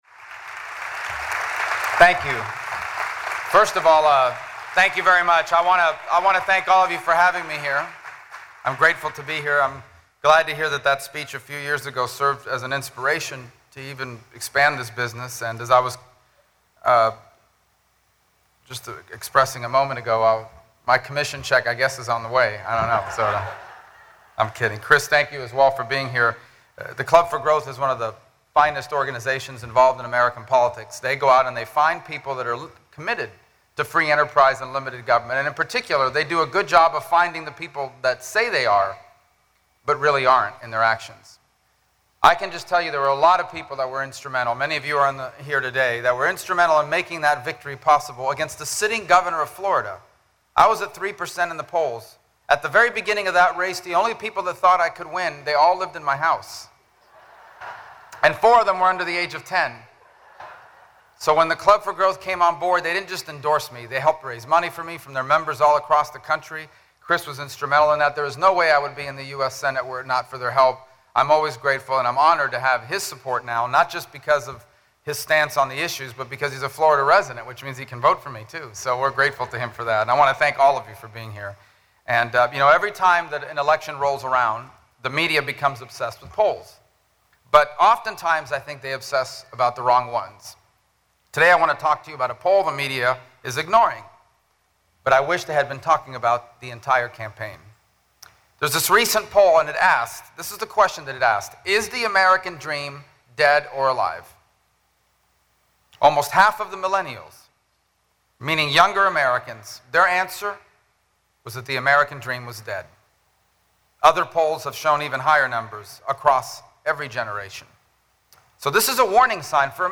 Florida Senator and Republican presidential candidate Marco Rubio made a rare appearance in the Tampa Bay area today, speaking at a boat manufacturer in Manatee County.
He came to Sarasota for a long-planned fundraiser, and added on a last-minute campaign stop with about 300 people at boat manufacturer Marine Concepts.
1-11_rubio_speech_in_sarasota.mp3